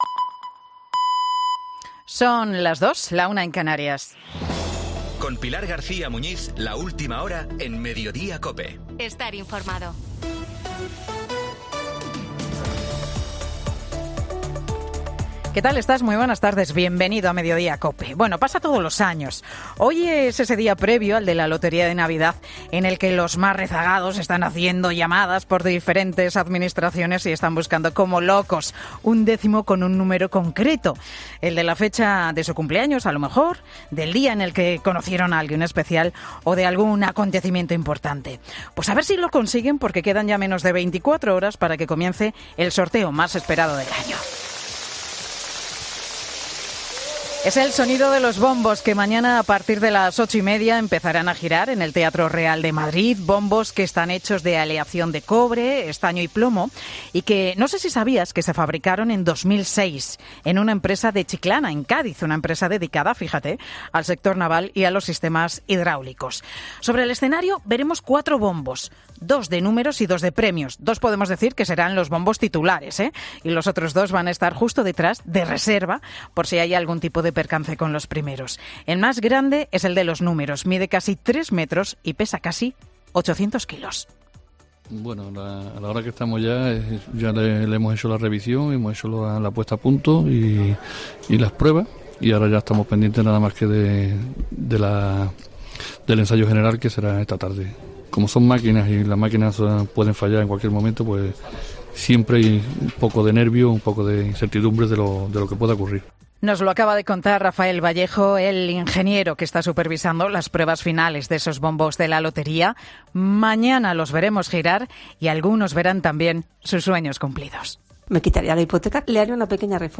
Escucha el monólogo de Pilar García Muñinz en 'Mediodía COPE'